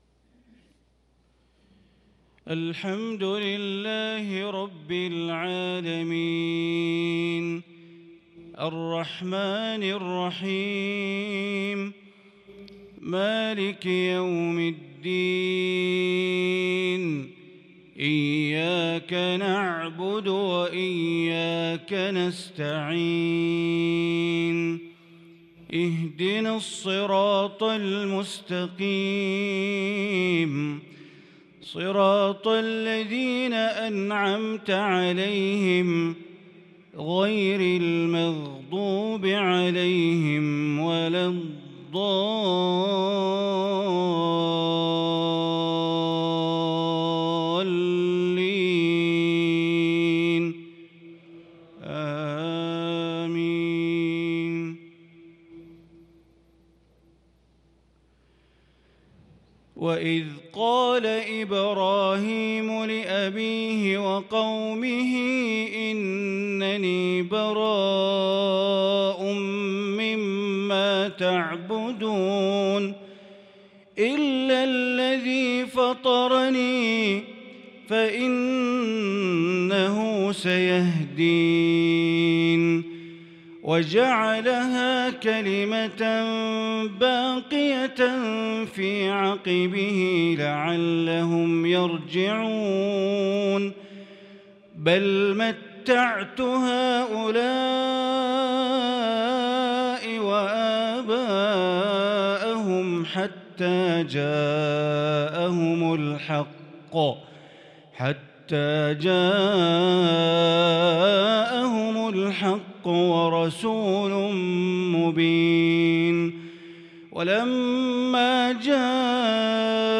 صلاة العشاء للقارئ بندر بليلة 30 ربيع الأول 1443 هـ
تِلَاوَات الْحَرَمَيْن .